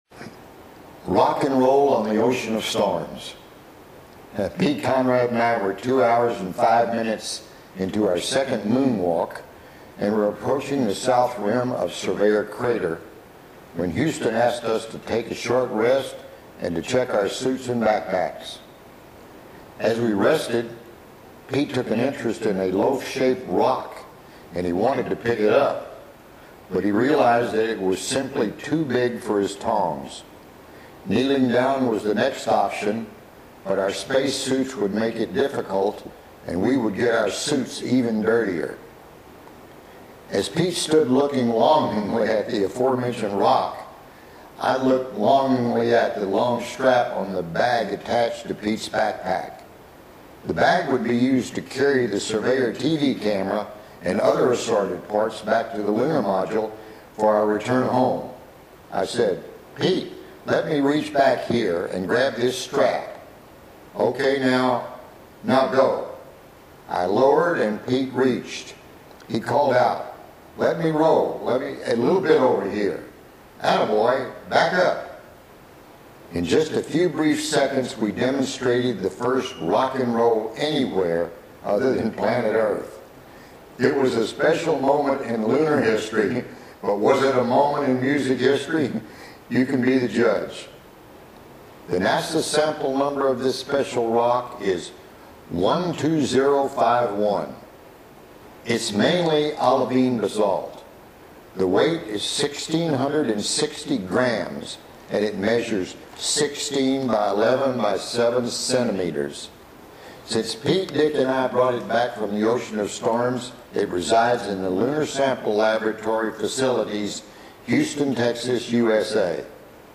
Click below to hear Alan Bean